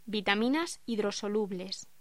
Locución: Vitaminas hidrosolubles